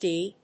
読み方デルタ